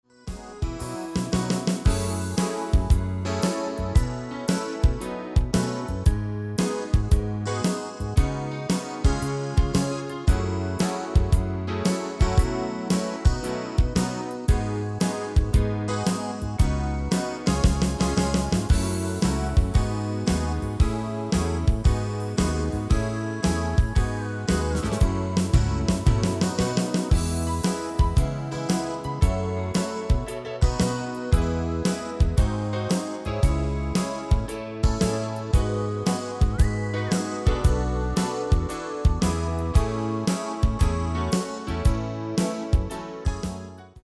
Demo/Koop midifile
Genre: Pop & Rock Internationaal
- GM = General Midi level 1
- Géén vocal harmony tracks